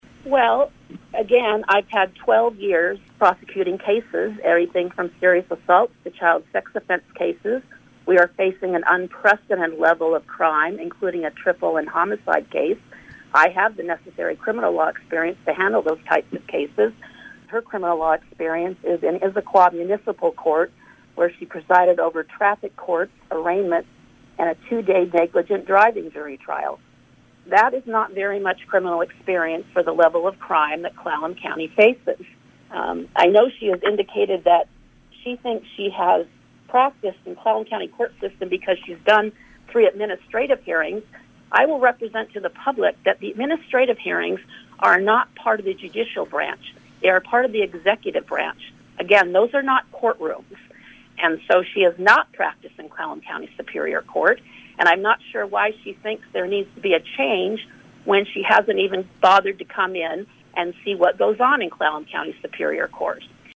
Superior Court candidates debate their qualifications